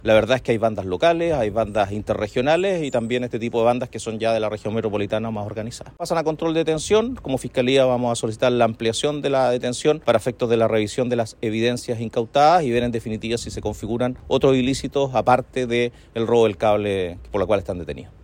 El fiscal regional subrogante, Michelangelo Bianchi, detalló que los sujetos tenían residencia en la región Metropolitana y que contaban con un amplio prontuario policial por el delito de robos, hurto, homicidio, amenazas y otros.
fiscal-robo-cable-talcahuano.mp3